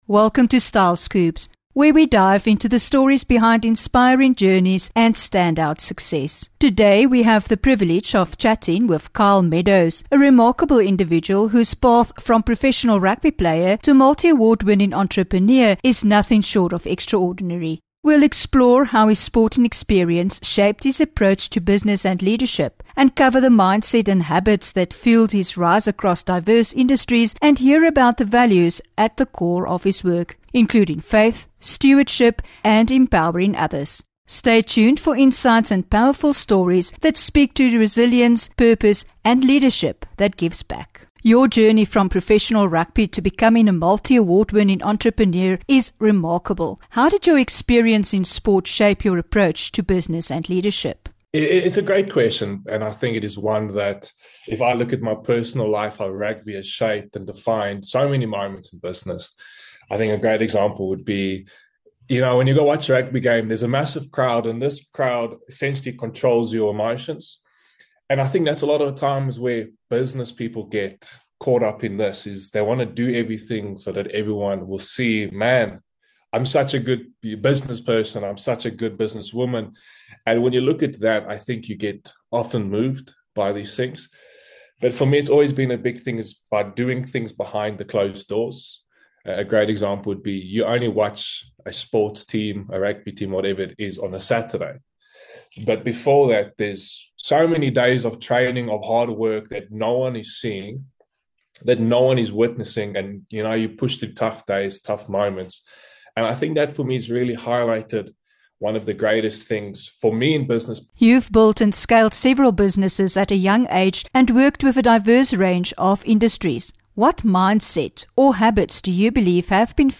2 Sep INTERVIEW